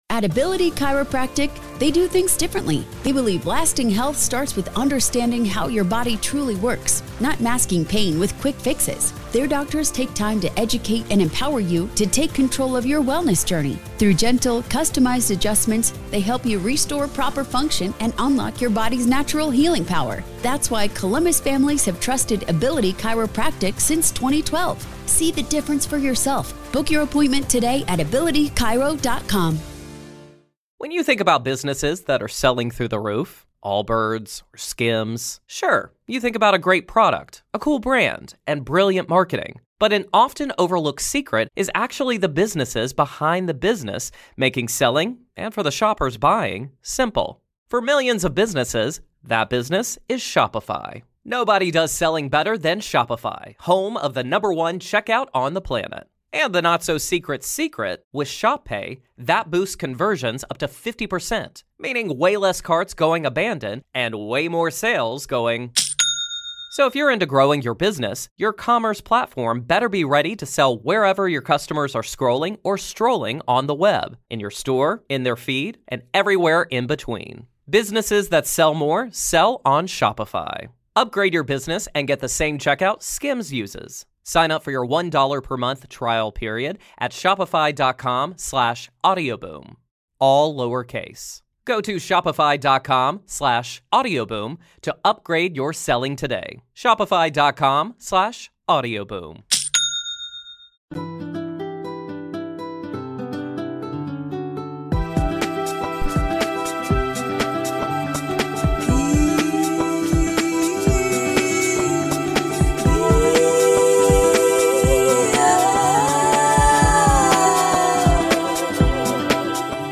Every show has awakening chats and interviews with incredible people from all around the world; light-workers, way-showers, truth speakers, earth keepers, love embracers, healers and therapists, and all those who are benefiting others and our planet in some way.